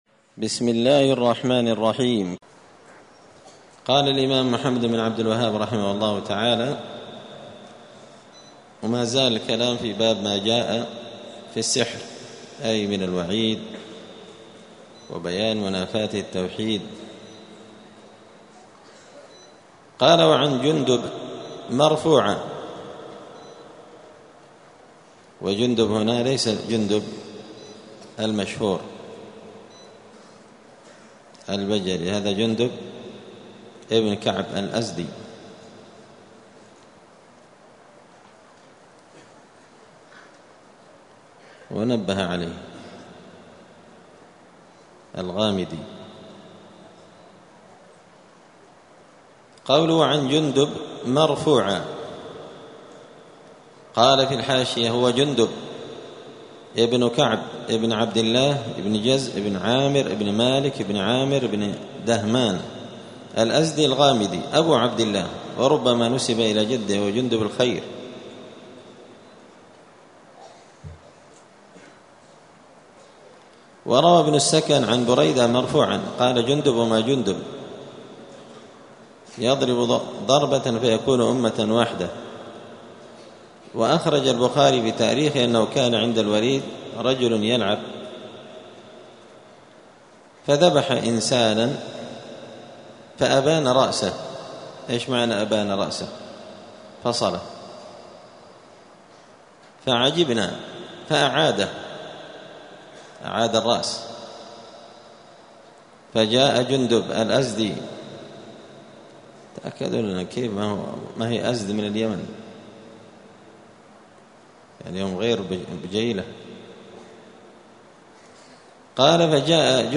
دار الحديث السلفية بمسجد الفرقان قشن المهرة اليمن
*الدرس التاسع والستون (69) {باب ماجاء في السحر}*